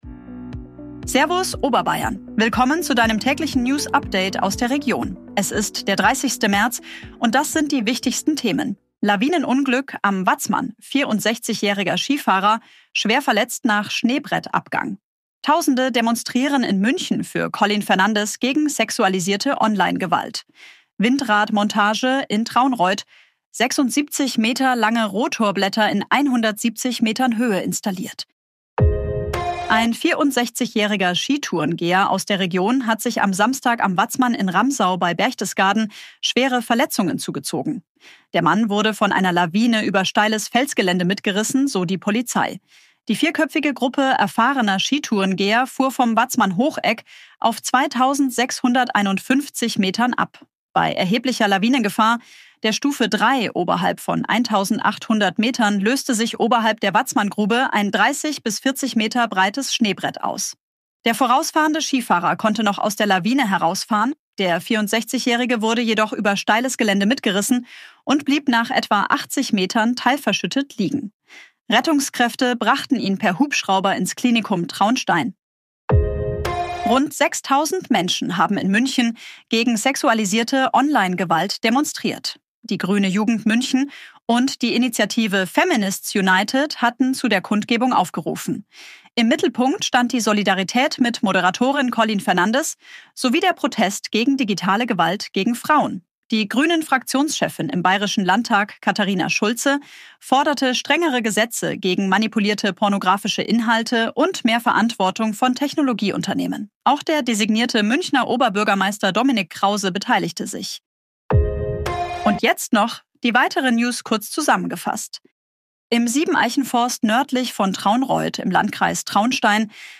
Tägliche Nachrichten aus deiner Region
Basis von redaktionellen Texten erstellt.